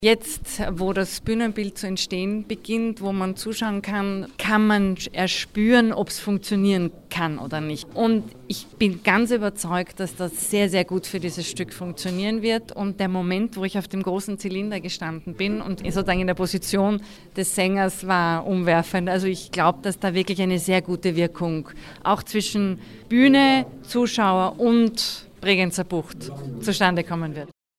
O-Ton Fototermin Alberschwende - news